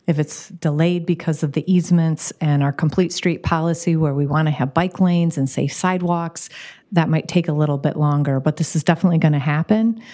Mayor Patricia Randall says a traffic study will be done.